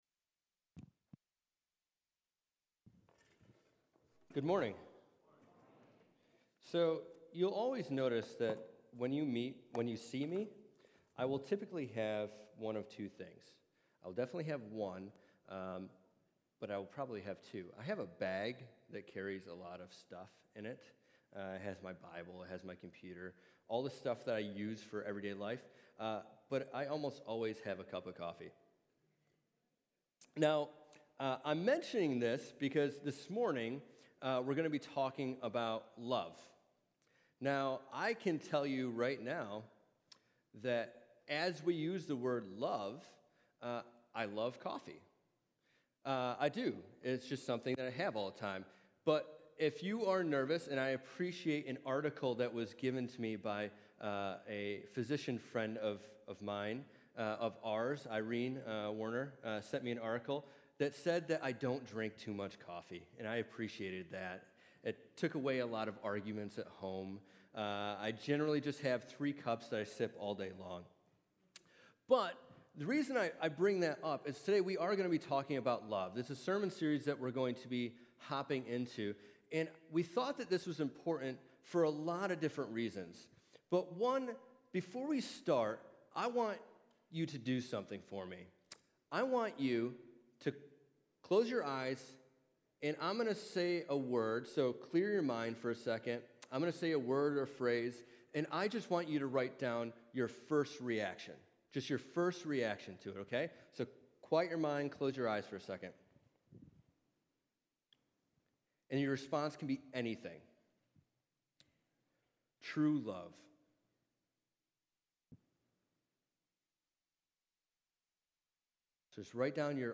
Sermon Archives | Syracuse Alliance Church